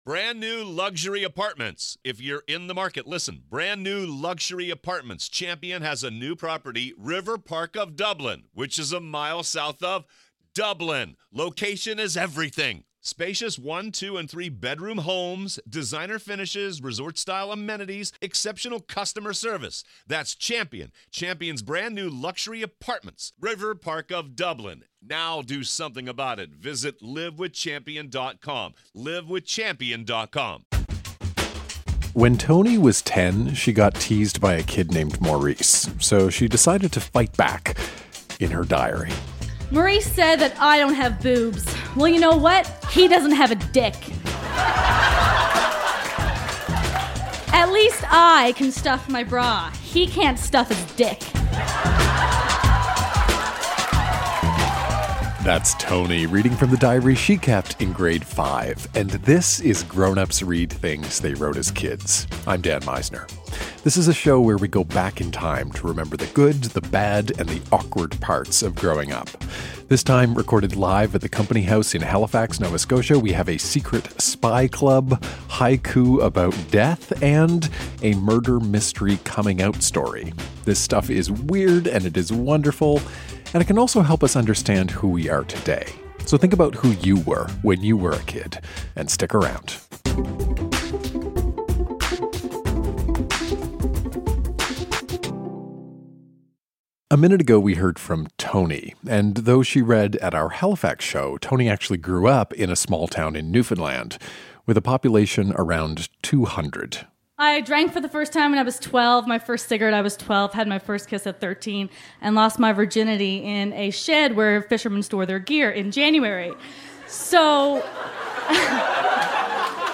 A secret spy club, haiku about death, and a murder-mystery coming out story. Recorded live at The Company House in Halifax, NS.